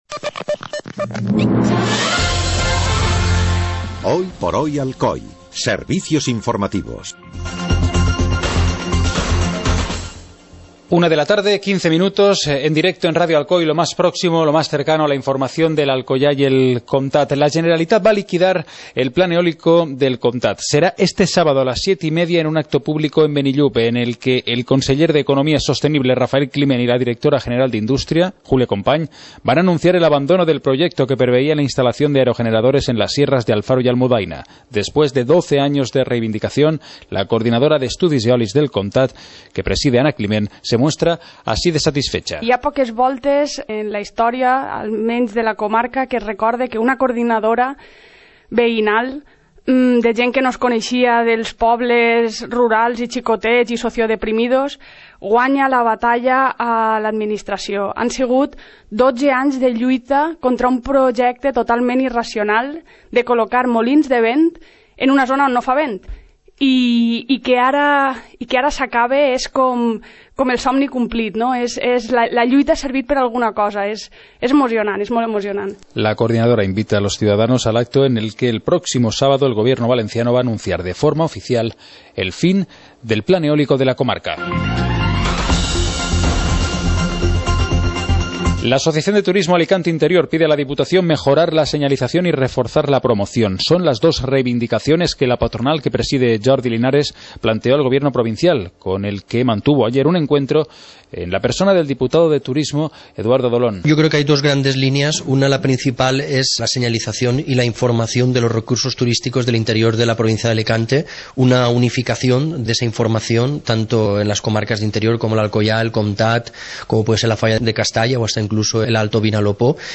Informativo comarcal - miércoles, 14 de octubre de 2015